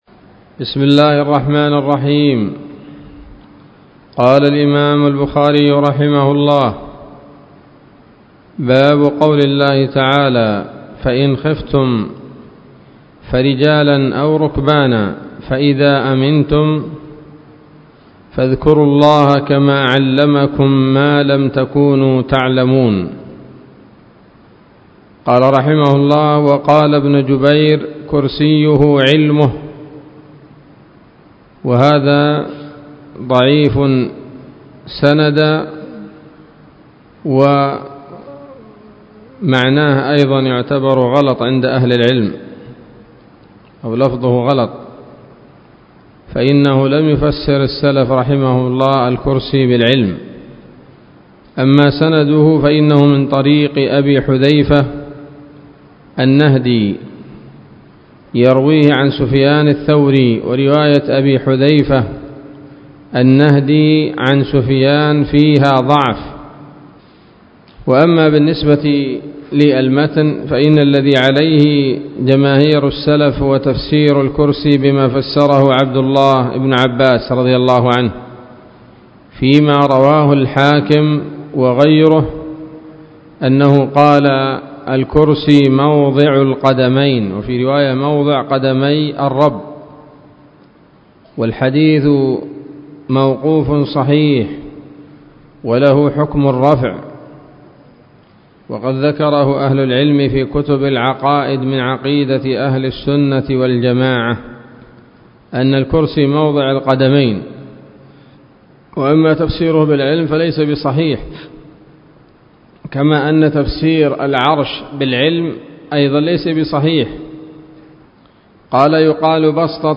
الدرس السابع والثلاثون من كتاب التفسير من صحيح الإمام البخاري